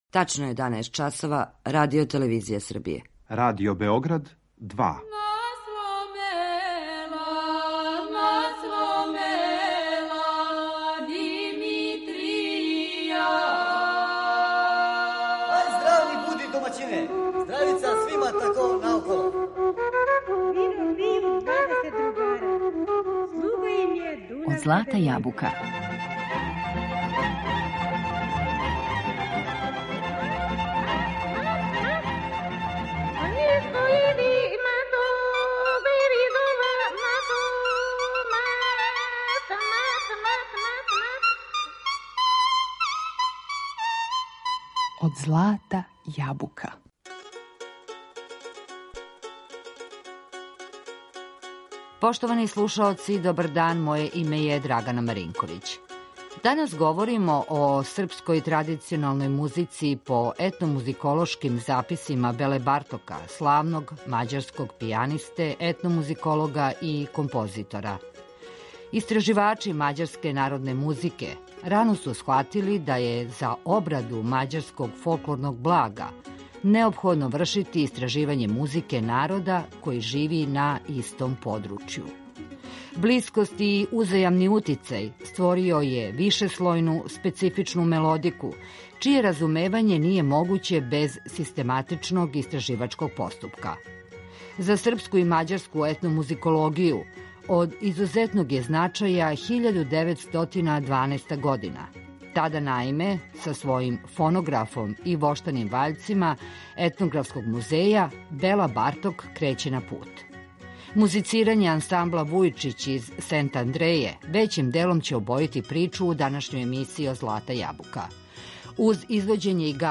Ансамбл „Вујичић" из Сентандреје већим делом обојиће музиком нашу данашњу причу, а осврнућемо се и на истраживачки рад и делатност композитора Тихомира Вујичића, који се бавио научним радом и сакупљао музичку традицију Срба, Буњеваца, Шокаца и Хрвата у Мађарској.